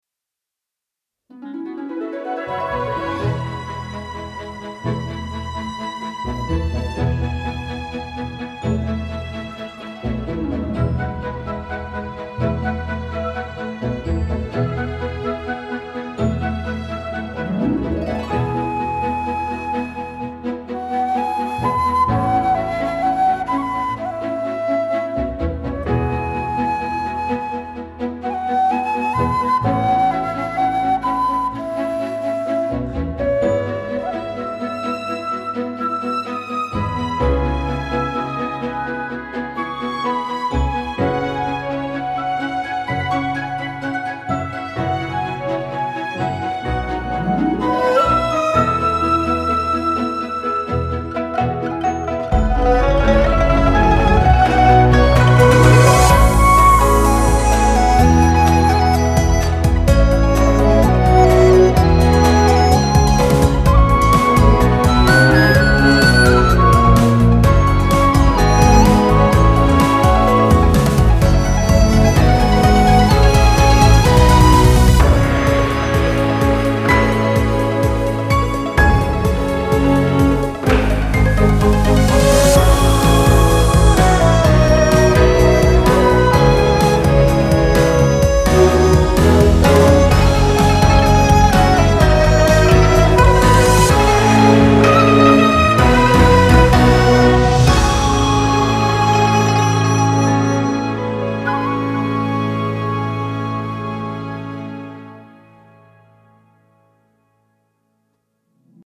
(Theme Music) BGM
This song gives me Chinese New Year vibes.